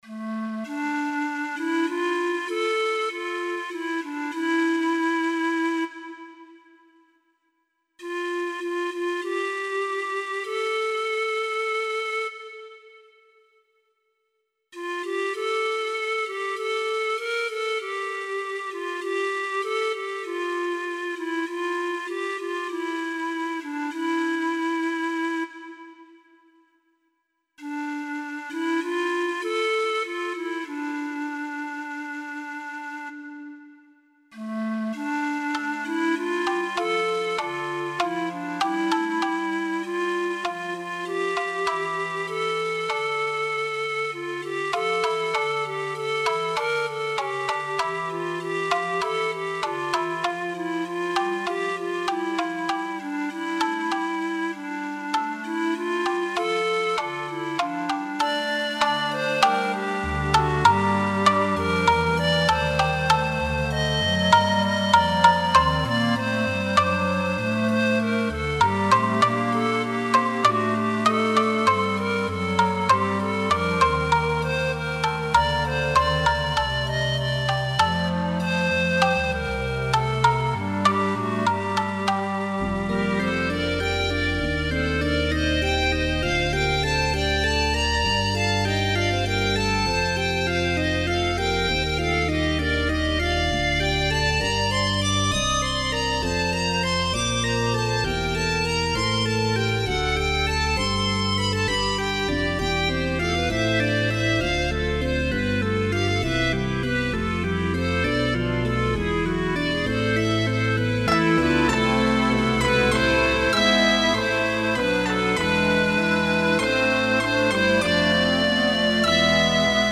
Ähnlich wie in dem vorherigen in Moll gehaltenen Lied werden hier mit der Metapher der Rosendornen die späteren Leiden des Christuskindes angedeutet – und wie schon in Es ist ein Ros' entsprungen entwickelt sich die Musik aus einer einfachen Ausgangssituation (Melodie ohne Begleitung) über einen Umkehrungskanon zu einem recht üppigen und herzergreifenden Choral.